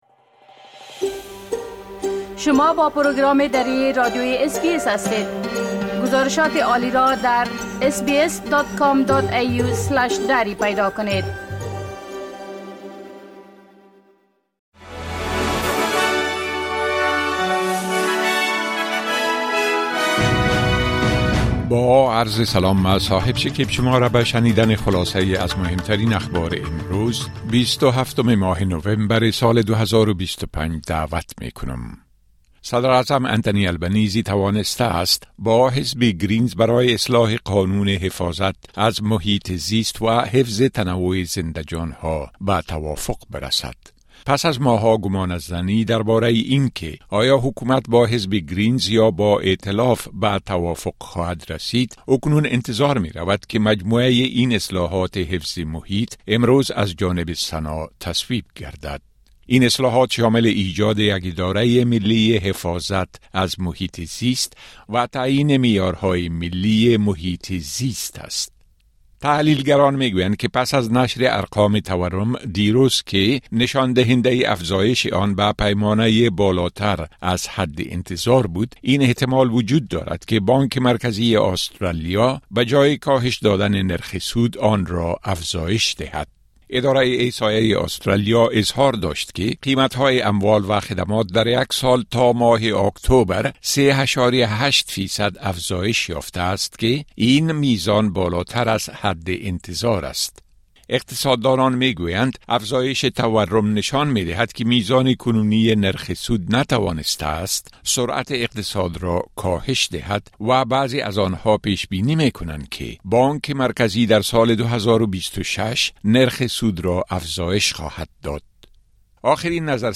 خلاصه مهمترين خبرهای روز از بخش درى راديوى اس‌بى‌اس